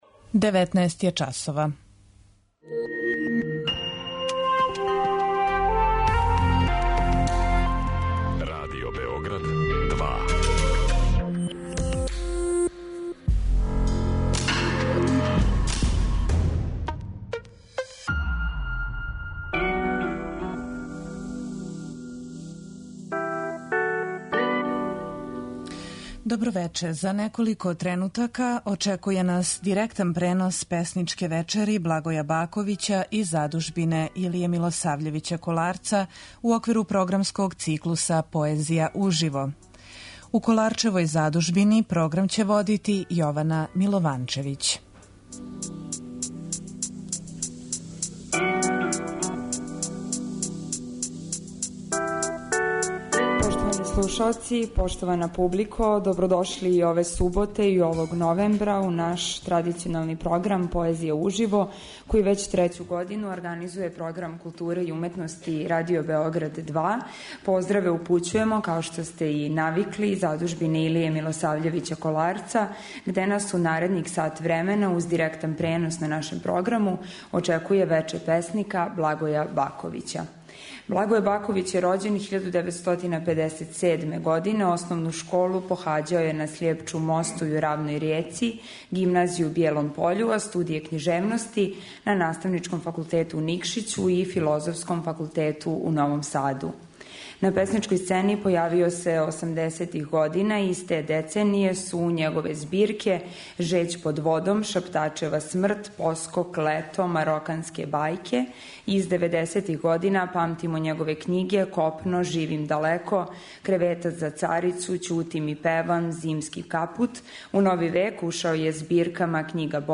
у сали Коларчеве задужбине
уз директан пренос на таласима Радио Београда 2.